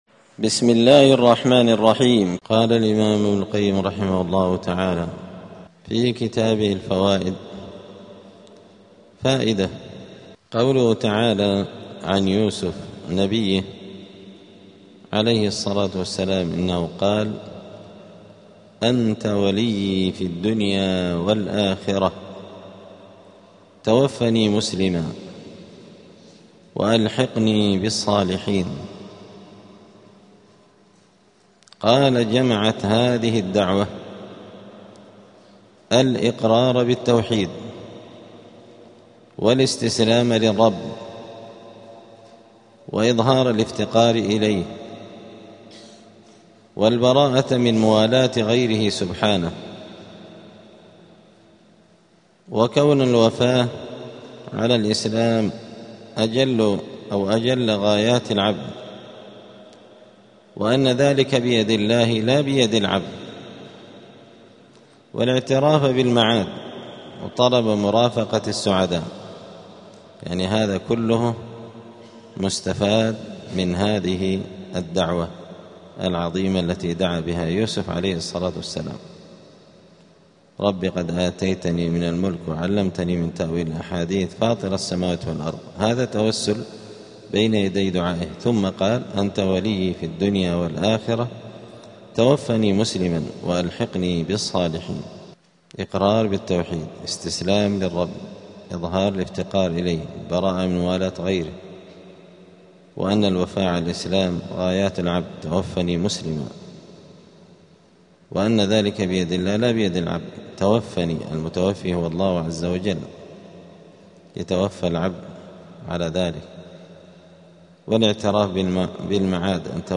الجمعة 14 جمادى الآخرة 1447 هــــ | الدروس، دروس الآداب، كتاب الفوائد للإمام ابن القيم رحمه الله | شارك بتعليقك | 7 المشاهدات
دار الحديث السلفية بمسجد الفرقان قشن المهرة اليمن